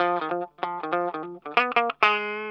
LO-FI 4R.wav